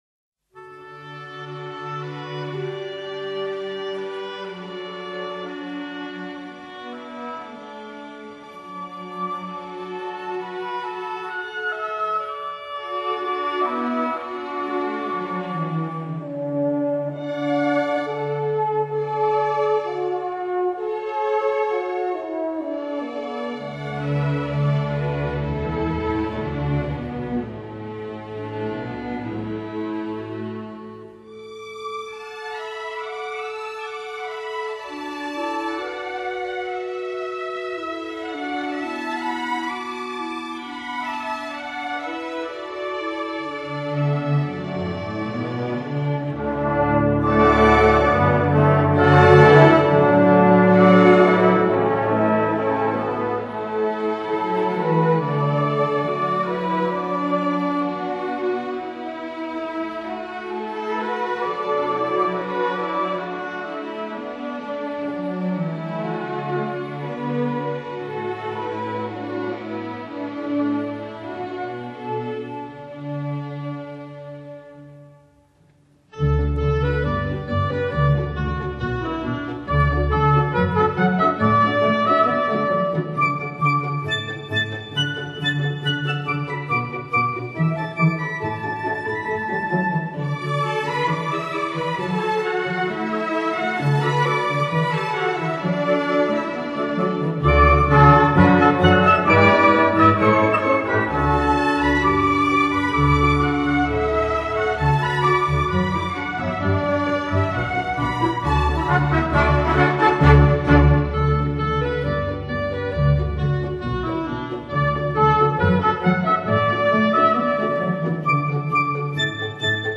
中国最经典交响乐作品收录